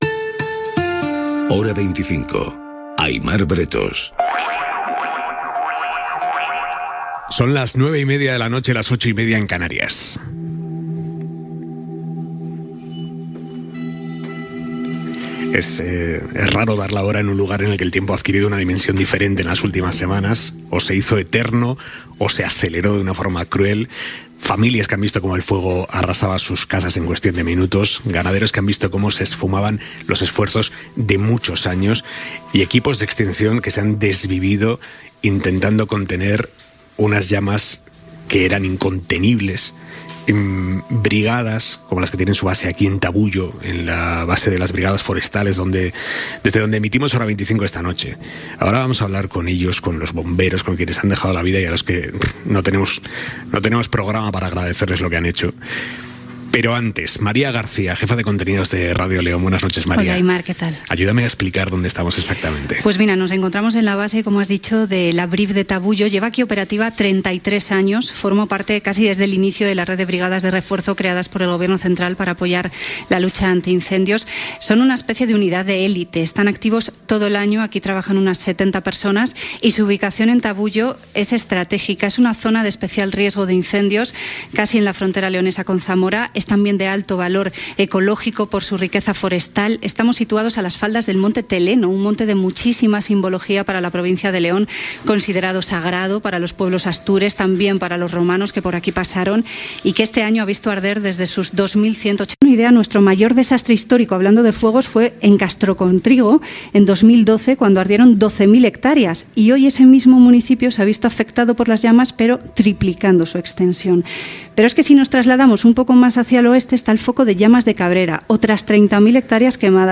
Fragment del programa emès des de Tabuyo (León)
Informatiu
FM